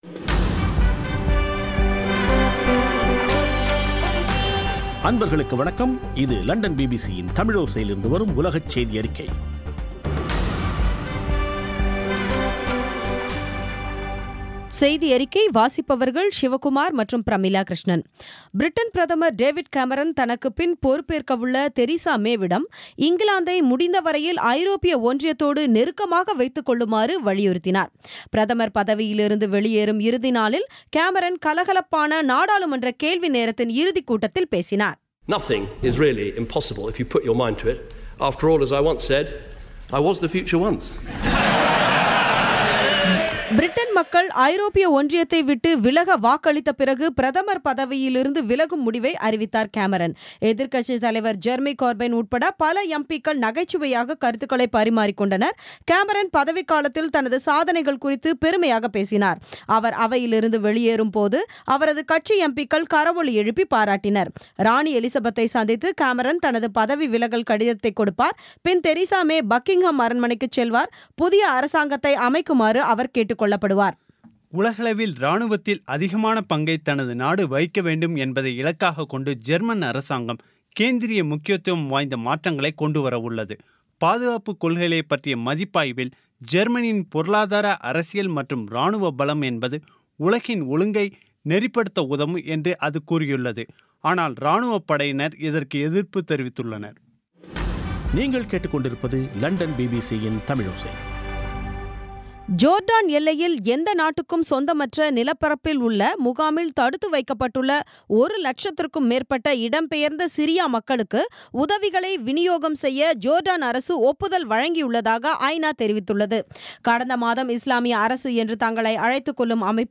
பிபிசி தமிழோசை செய்தியறிக்கை (13.07.2016)